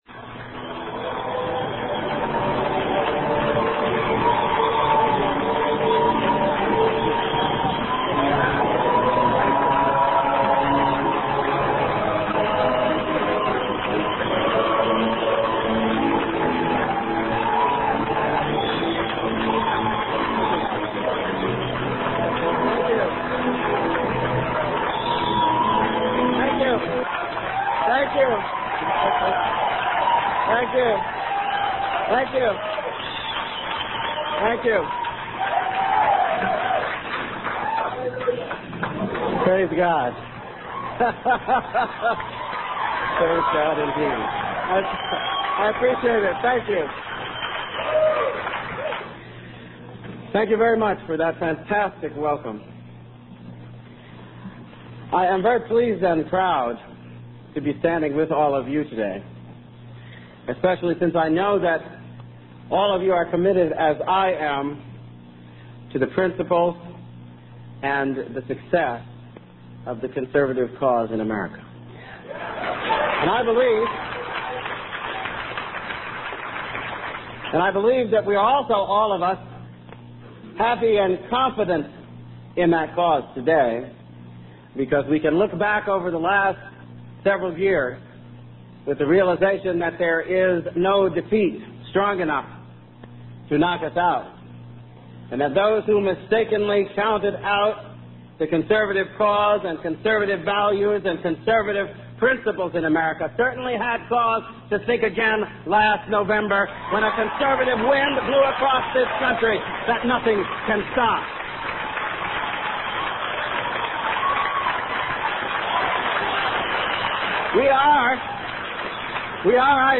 MP3 audio Speech Candidacy Announcement for the 1996 Presidential Election Alan Keyes March 25, 1995 California Republican Assembly in San Diego Praise God.